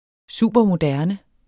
Udtale [ ˈsuˀbʌˈ- ]